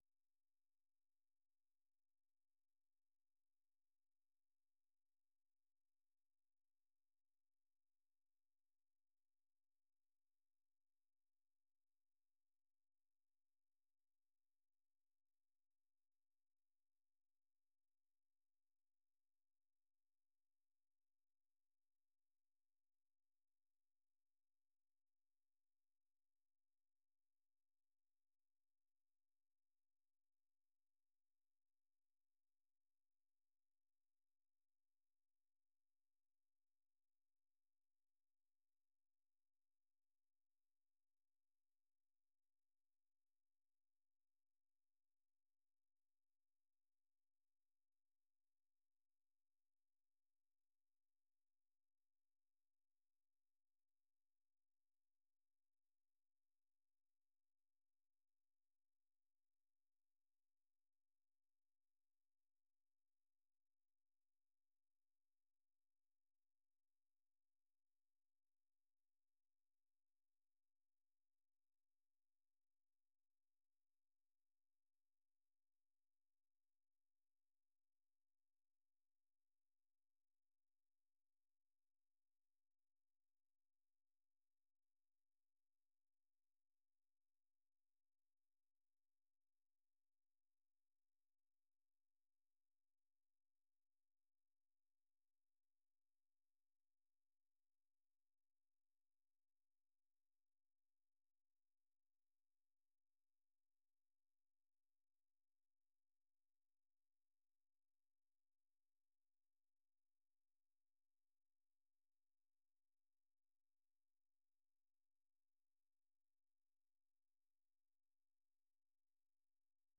ข่าวสดสายตรงจากวีโอเอ ภาคภาษาไทย 6:30 – 7:00 น.